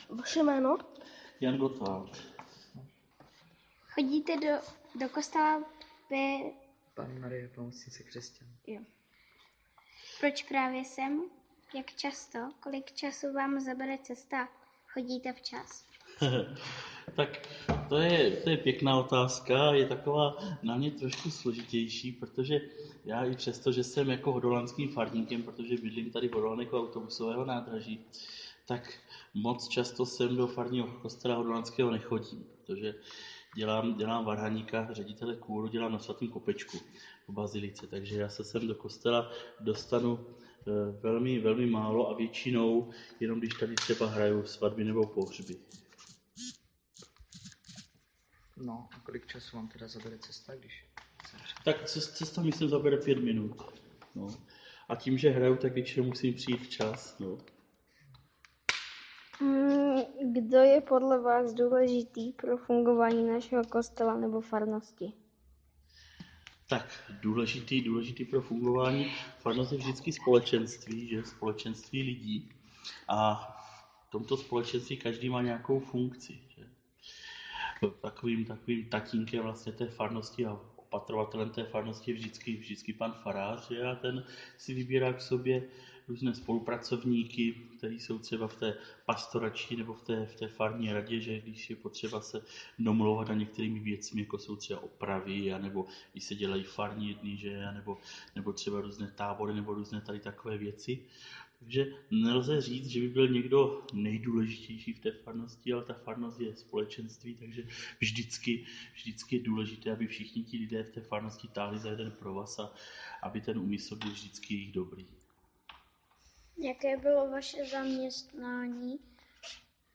Rozhovor
Poslechněte si v nesetříhané verzi rozhovor